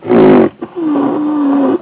c_rhino_hit2.wav